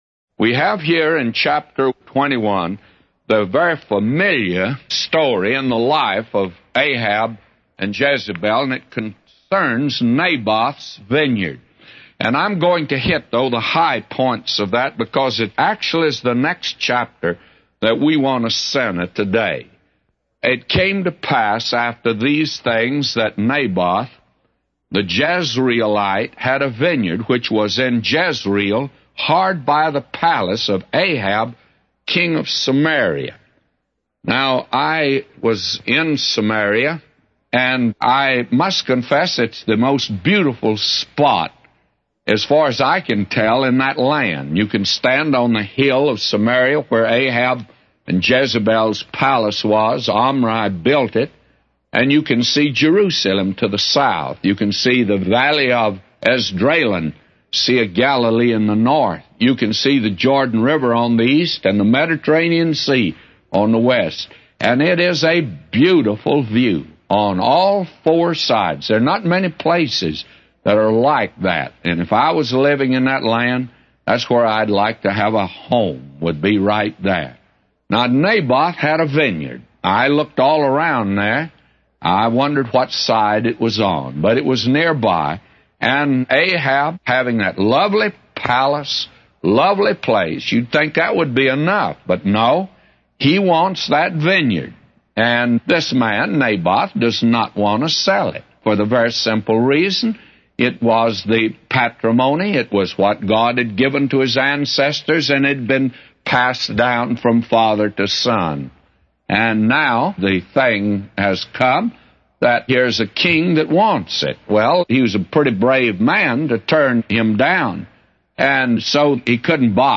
A Commentary By J Vernon MCgee For 1 Kings 21:1-999